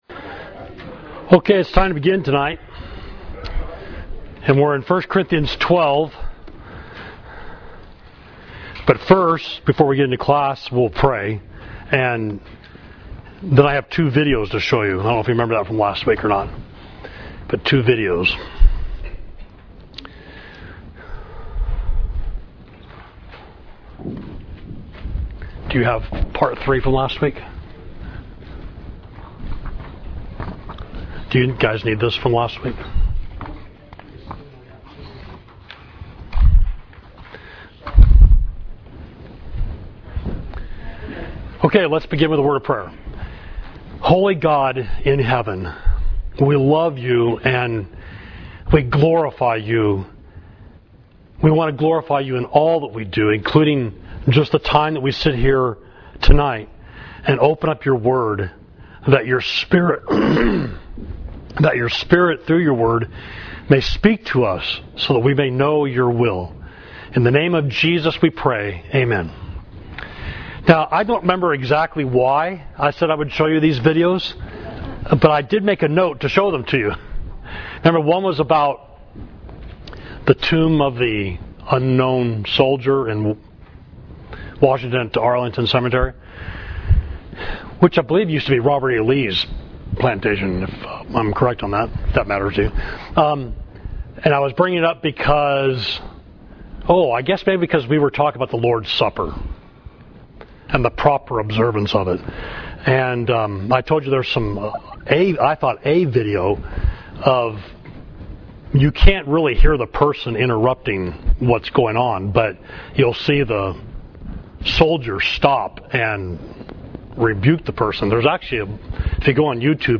Class: Matters of Worship: Part 3, Spiritual Gifts